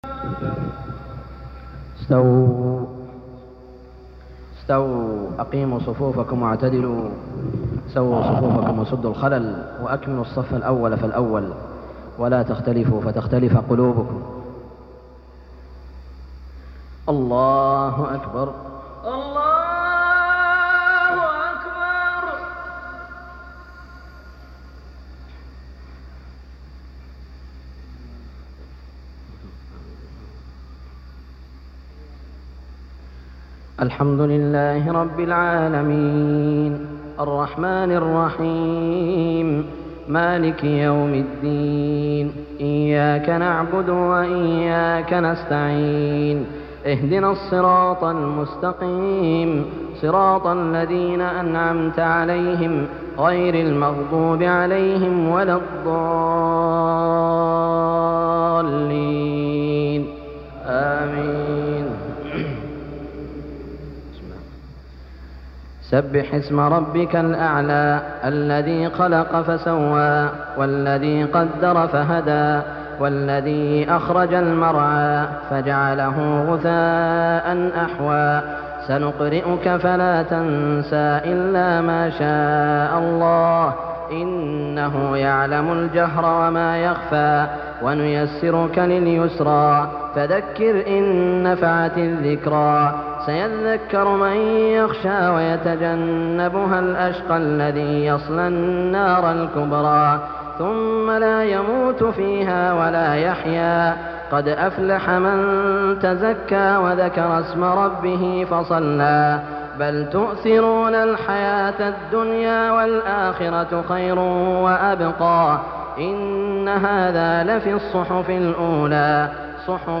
صلاة الجمعة 2-7-1416هـ سورتي الأعلى و الغاشية > 1416 🕋 > الفروض - تلاوات الحرمين